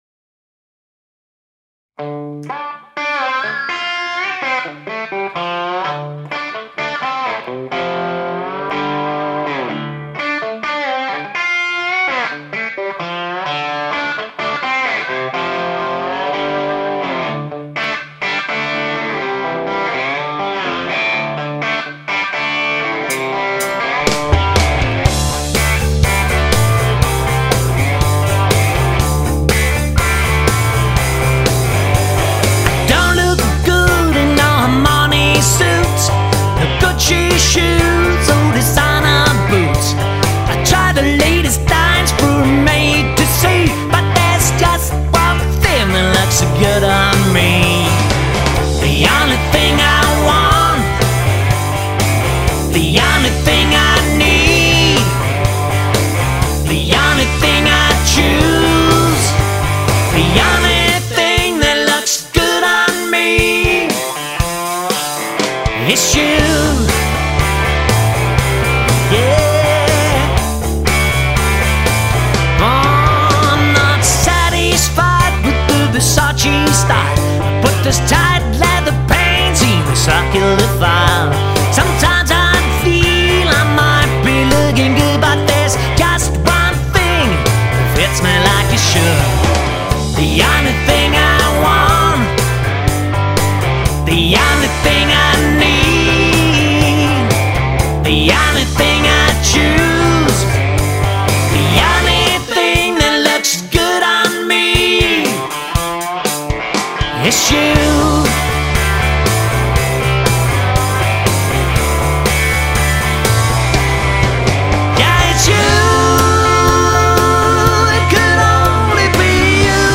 - er en trio bestående af Guitar, bas og trommer.
• Coverband
• Rockband